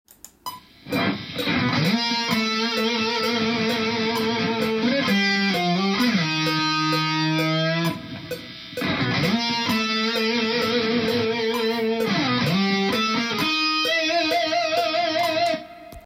ここで危険度を上げるために危険なリードギターを入れていきます。
音のヤバい感じのするエフェクターを選択し
音使いもテンションを入れて、緊張感マックスで